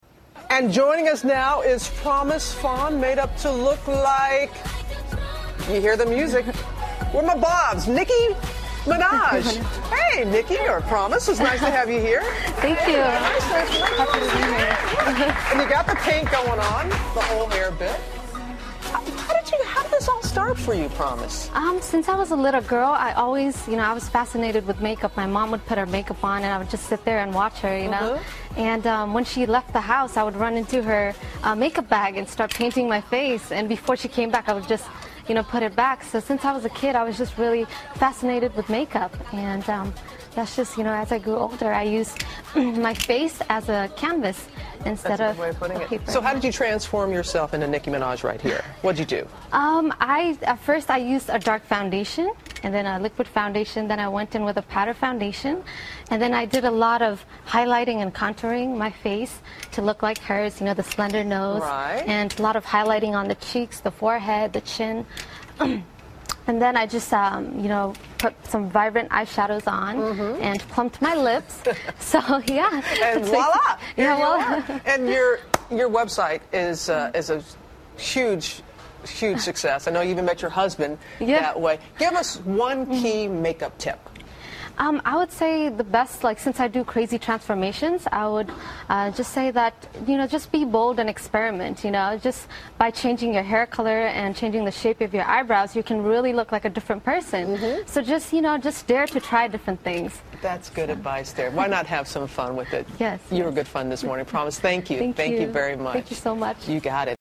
访谈录 2012-04-30&05-02 真人变色龙谈化妆秘密 听力文件下载—在线英语听力室